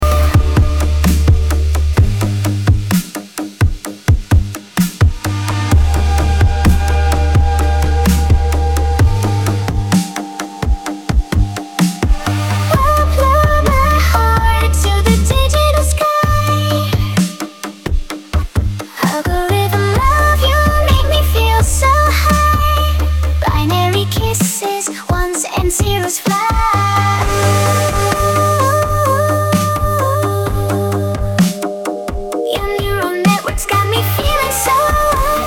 music-generation music-style-transfer singing-voice-generation voice-cloning
Quickly generate up to 1 minute of music with lyrics and vocals in the style of a reference track